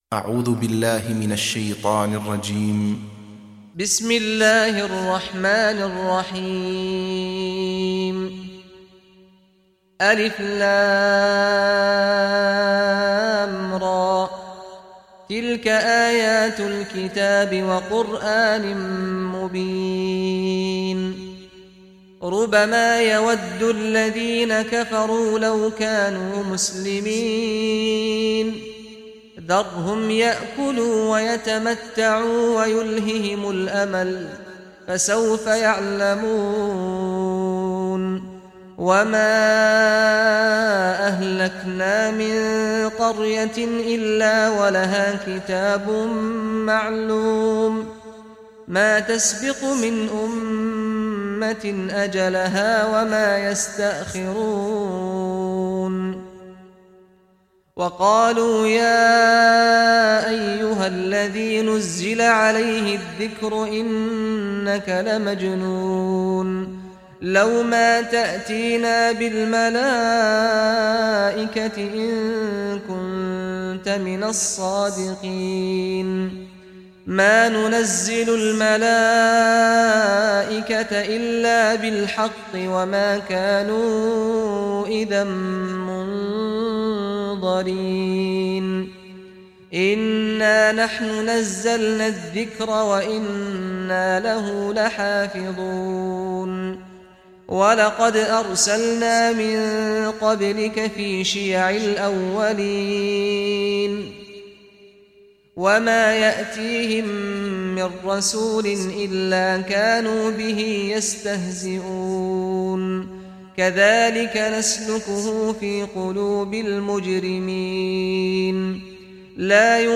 Surah Al-Hijr Recitation by Sheikh Saad Al Ghamdi
Surah Al-Hijr, listen or play online mp3 tilawat / recitation in Arabic in the beautiful voice of Sheikh Saad al Ghamdi.